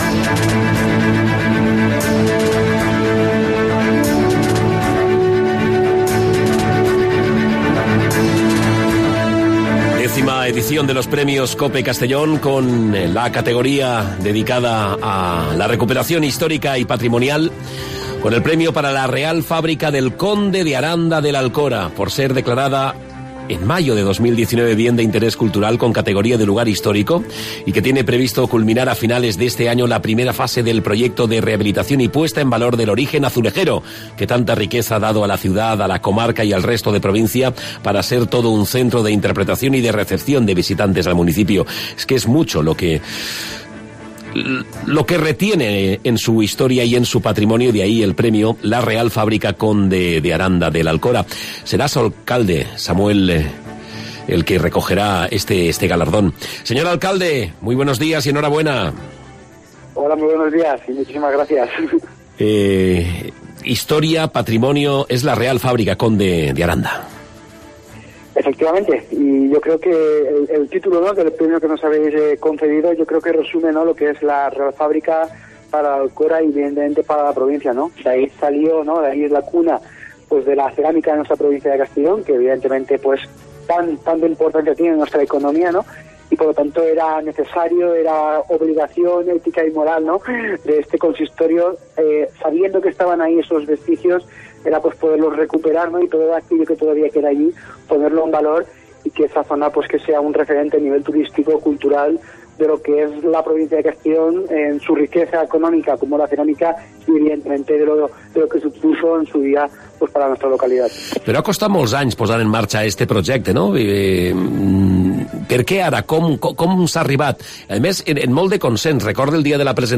Entrevista a Samuel Falomir (Real Fábrica del Conde de Aranda de l'Alcora)